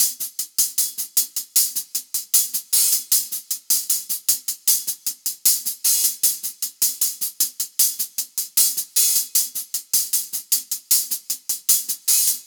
views hi hat loop.wav